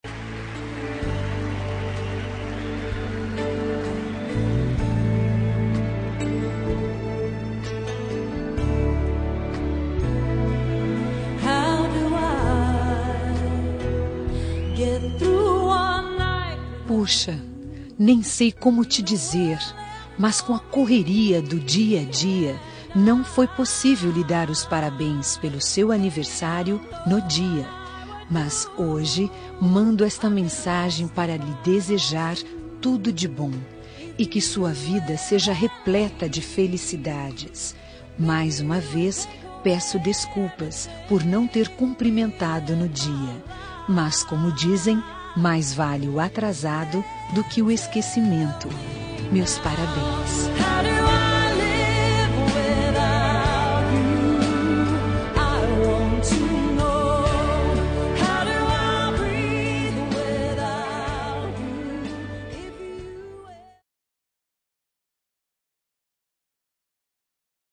Aniversário Atrasado – Voz Feminina – Cód: 2488